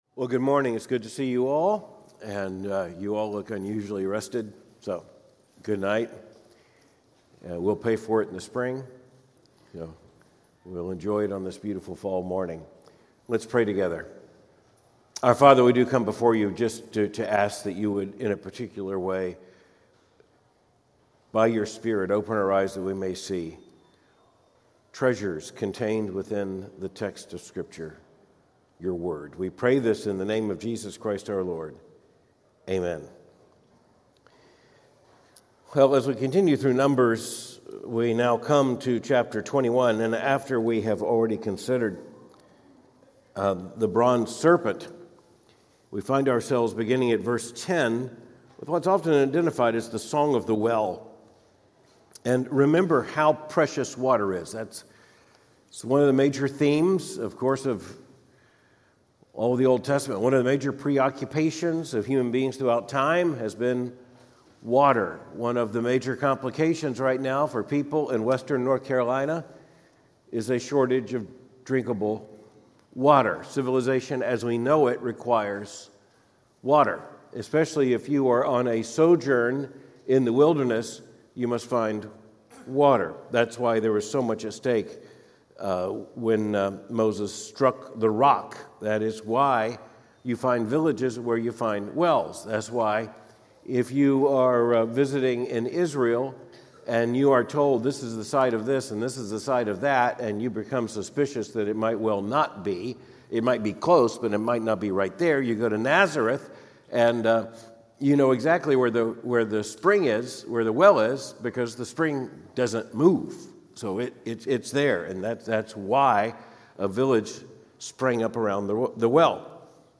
Cultural commentary from a Biblical perspective Third Avenue Baptist Church Louisville, KY Numbers 21:10-24:25 November 3, 2024